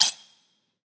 意式咖啡吧和咖啡机样品 " 21d. 将杯子堆放在咖啡机上
描述：将卡布奇诺和浓缩咖啡杯堆放在浓缩咖啡机上
Tag: 场记录 浓咖啡 咖啡 酒吧